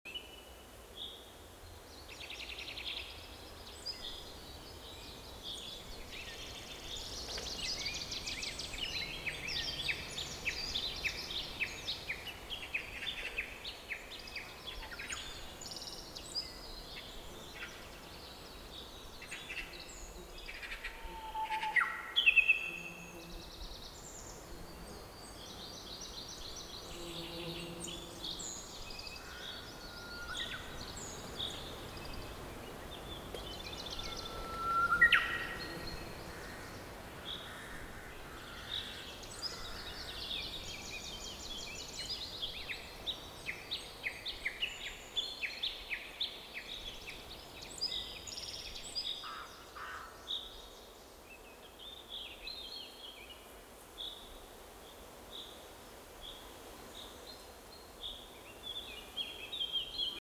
Forest Loop.ogg